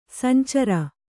♪ sancara